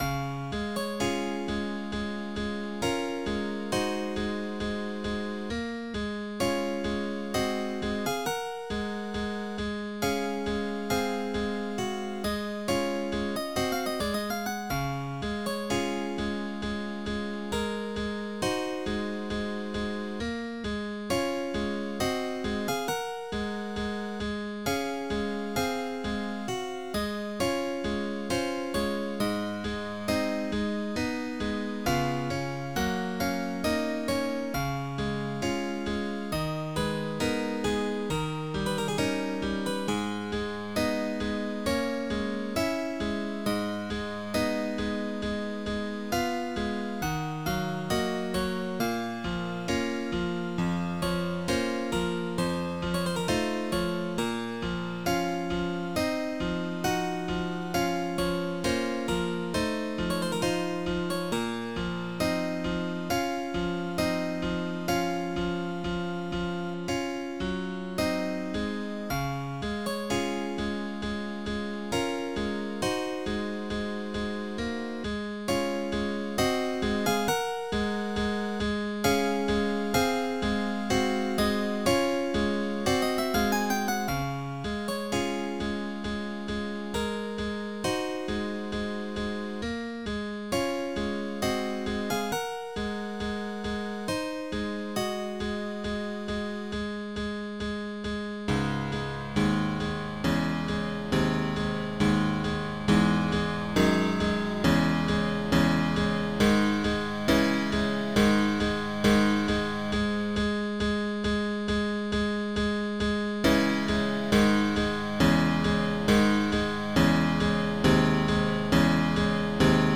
Protracker and family
** in D-flat Major **